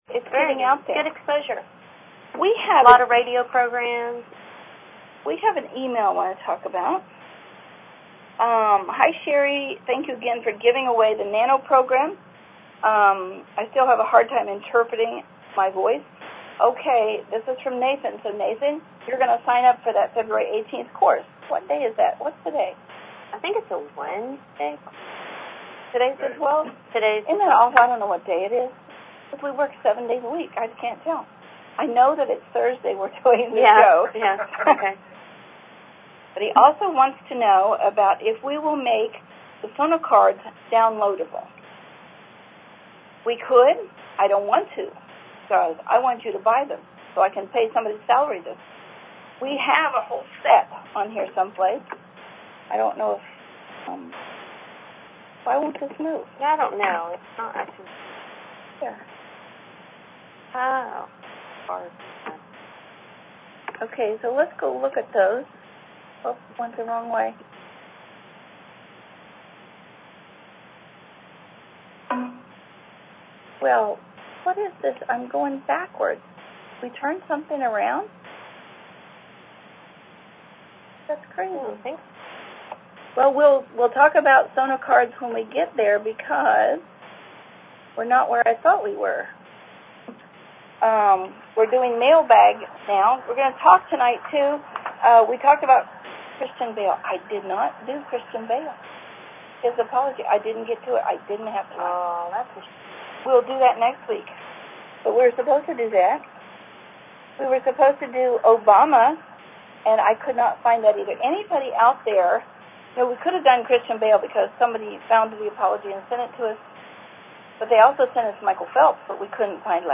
Talk Show Episode, Audio Podcast, Vocal_Profiling_Live and Courtesy of BBS Radio on , show guests , about , categorized as
Calls, Questions & Comments The Viewers Speak Out!